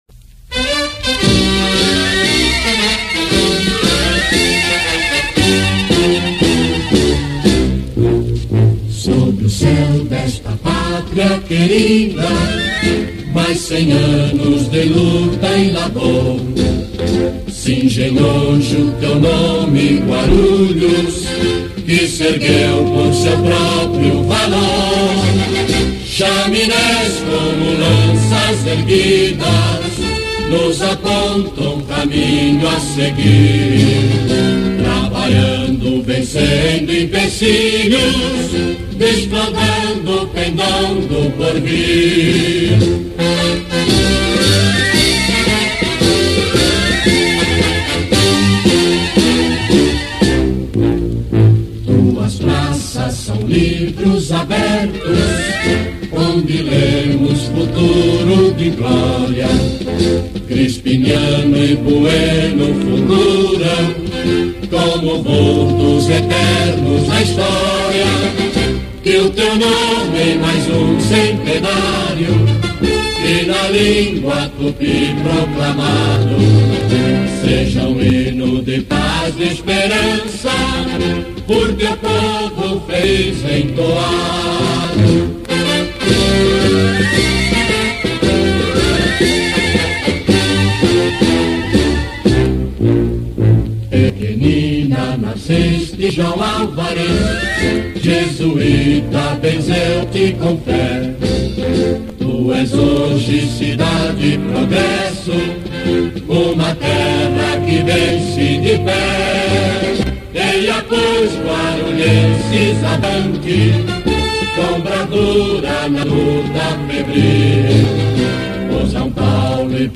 Cantado